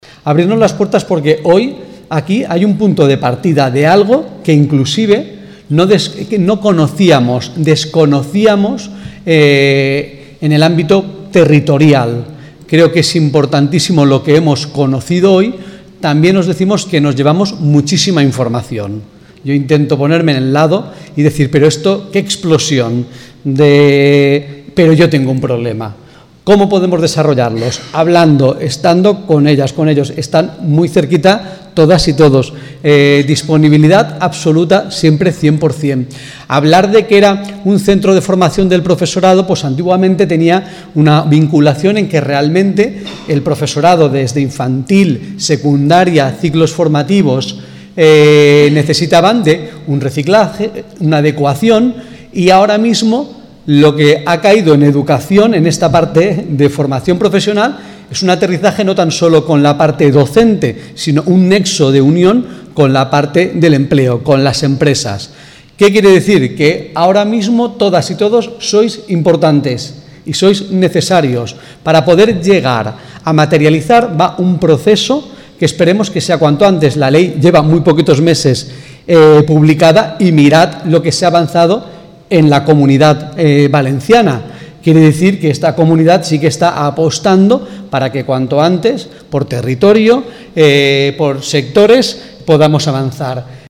Interesante charla debate sobre empleabilidad y formación del sector del calzado